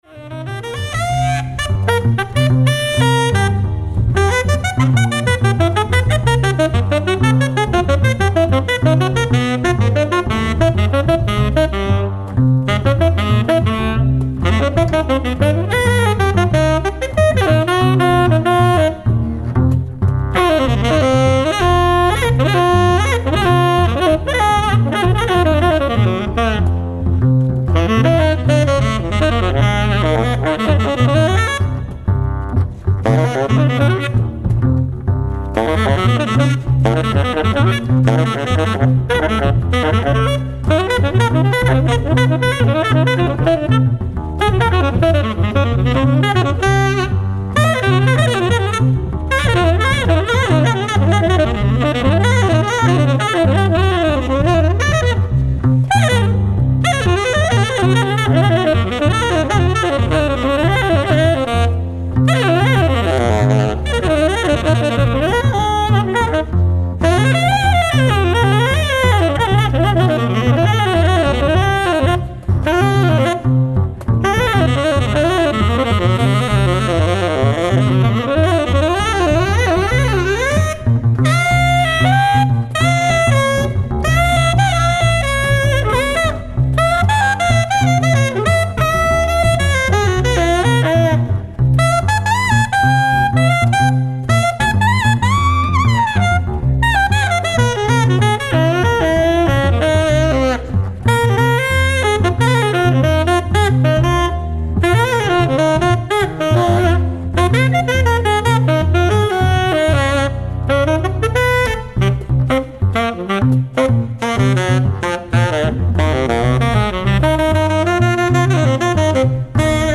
ライブ・アット・ヴィリザウ、スイス
※試聴用に実際より音質を落としています。